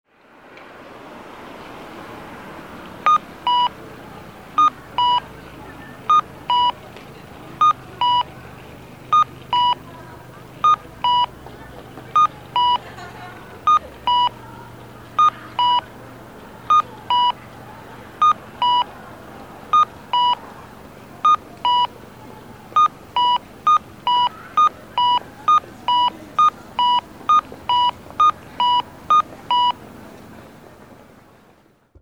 交通信号オンライン｜音響信号を録る旅｜大分県の音響信号｜[別府:0076]北浜公園前
北浜公園前(大分県別府市)の音響信号を紹介しています。